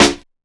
Snare (8).wav